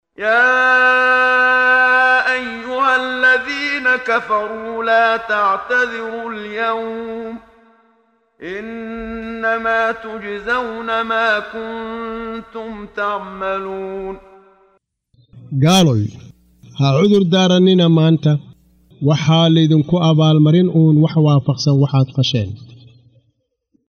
Waa Akhrin Codeed Af Soomaali ah ee Macaanida Surah At-Taxriim ( Iska xaaraantimeynta ) oo u kala Qaybsan Aayado ahaan ayna la Socoto Akhrinta Qaariga Sheekh Muxammad Siddiiq Al-Manshaawi.